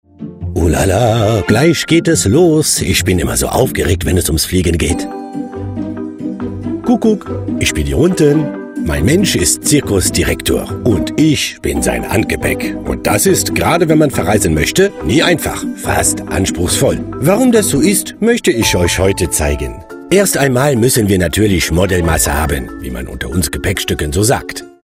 VISA Werbung